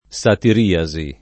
satiriasi [ S atir & a @ i ] s. f. (med.)